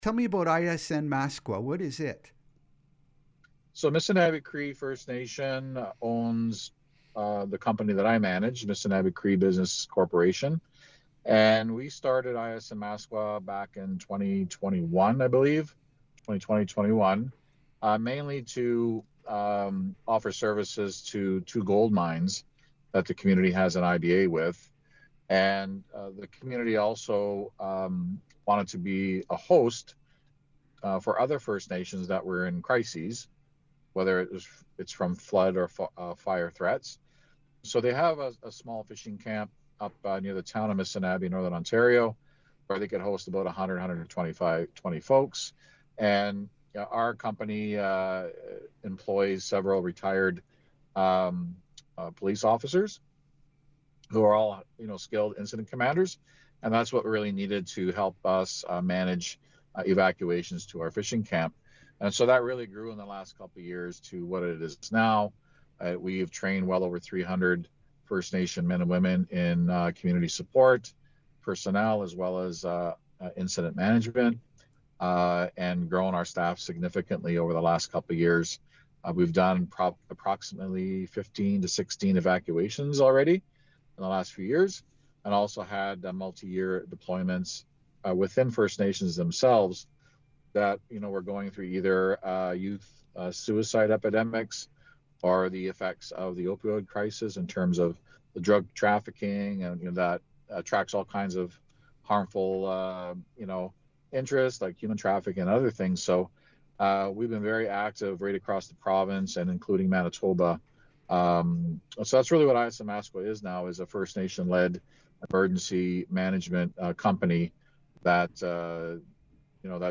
There’s much more about ISN Maskwa and how it works, in the audio of our interview, right here: